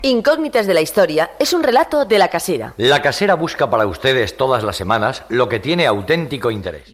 Identificació del programa amb publicitat
Programa publicitari presentat per José Luis Pécker.